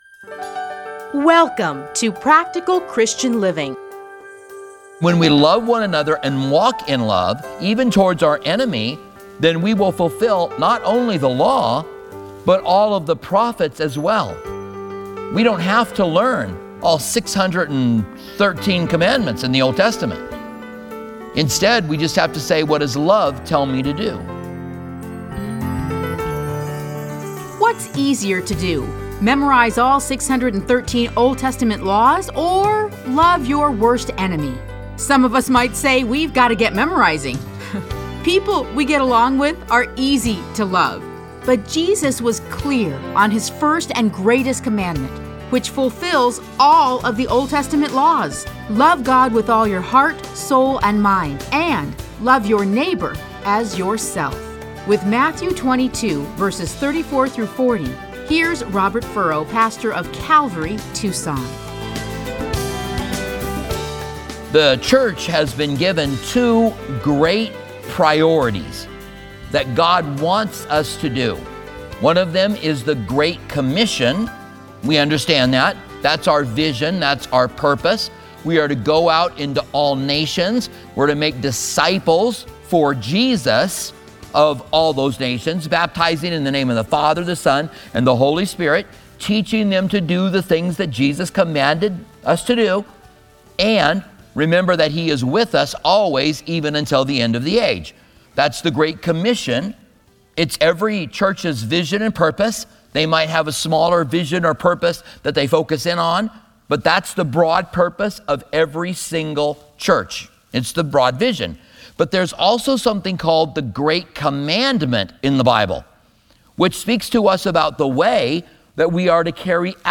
Listen to a teaching from Matthew 22:34-40.